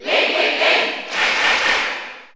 Crowd cheers (SSBU)
Link_&_Toon_Link_Cheer_German_SSBU.ogg